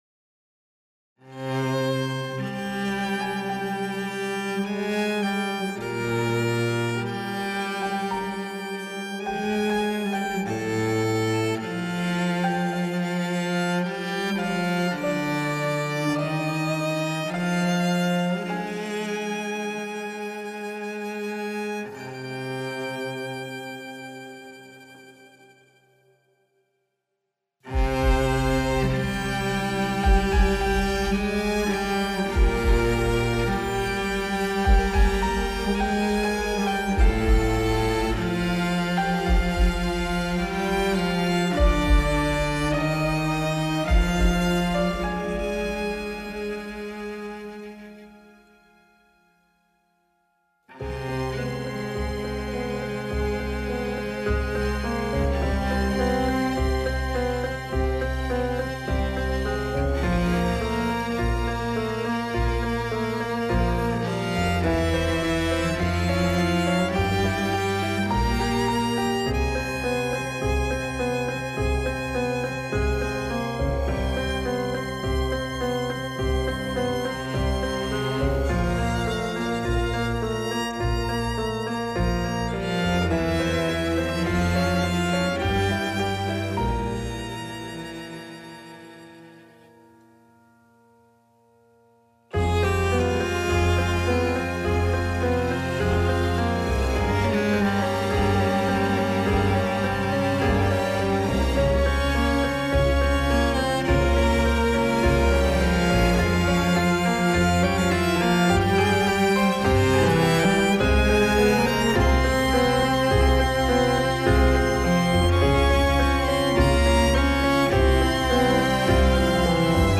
P.S. Соло скрипка и виолончель будут позже заменены на настоящие.